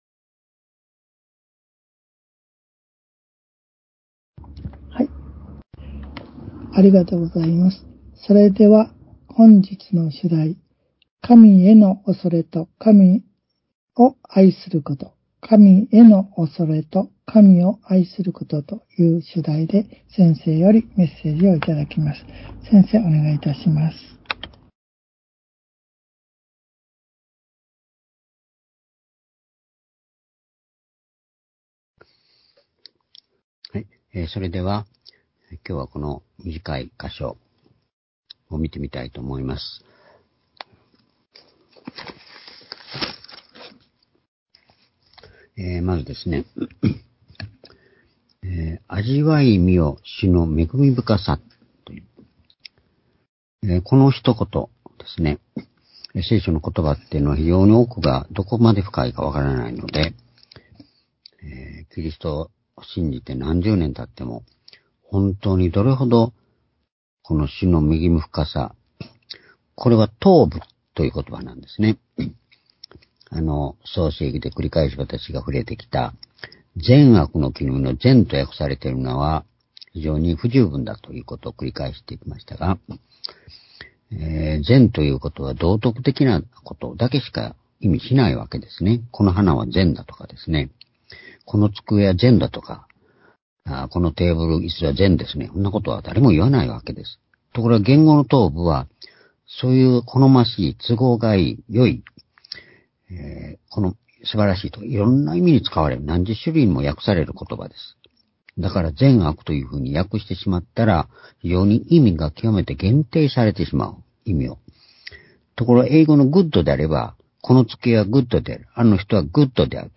（主日・夕拝）礼拝日時 ２０２３年４月４日（夕拝） 聖書講話箇所 「神への畏れと神を愛すること」 詩篇３４の９-１１ ※視聴できない場合は をクリックしてください。